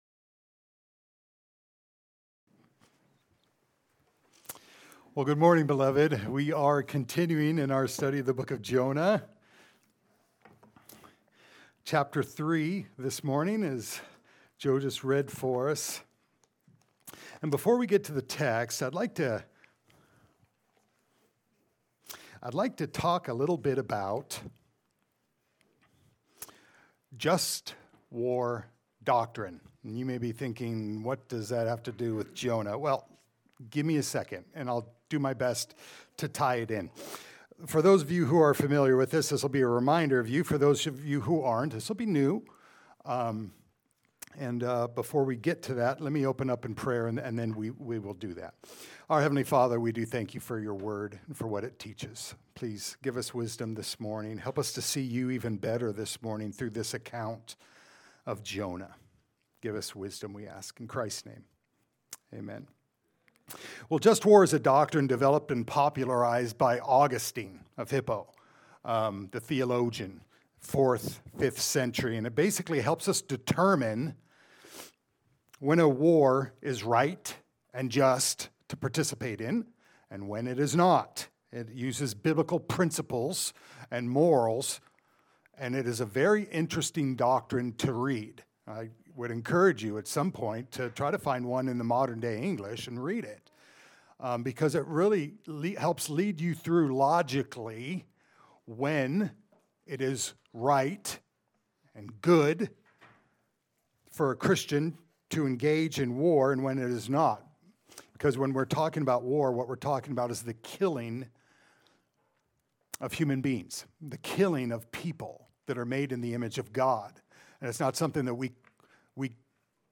Jonah Passage: Jonah 3:1-10 Service Type: Sunday Service « “Jonah…